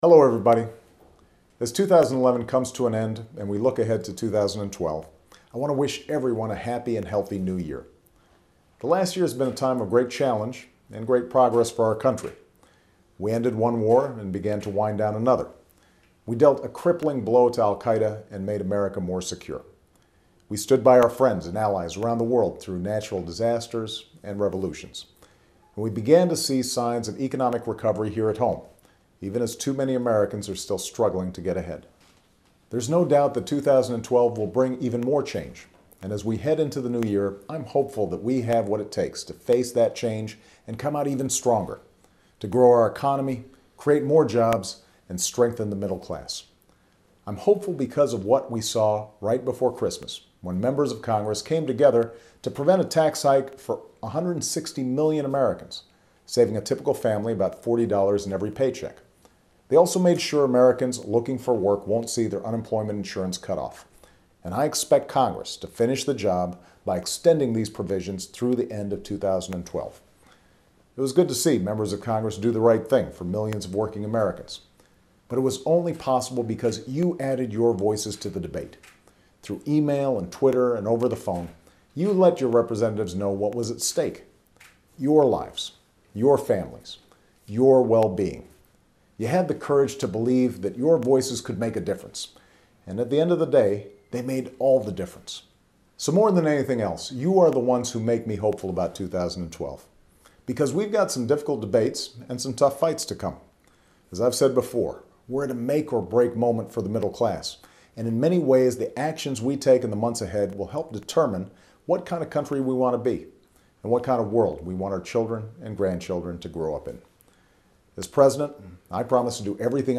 Remarks of President Barack Obama
Weekly Address
Honolulu, HI